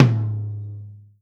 Tom 02.wav